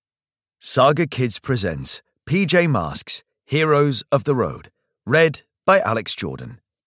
PJ Masks - Heroes of the Road (EN) audiokniha
Ukázka z knihy